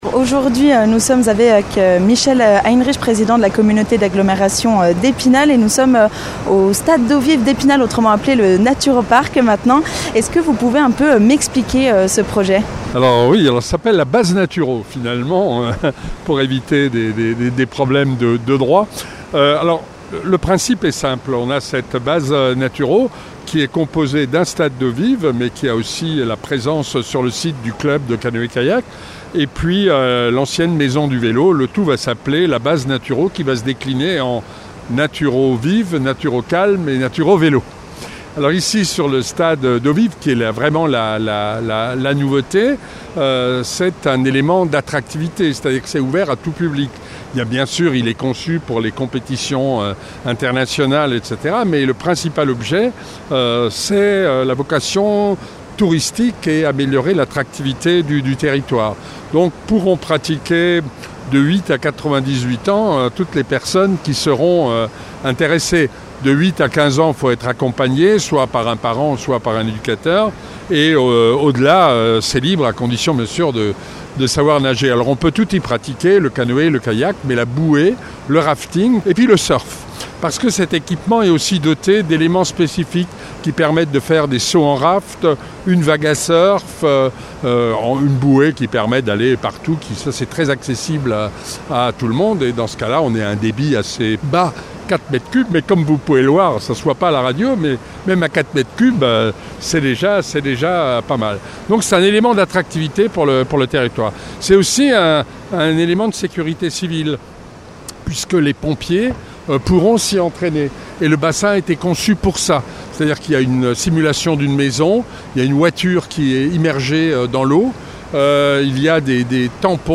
Reportage avec Michel Heinrich, président de la Communauté d'Agglomérations d'Epinal.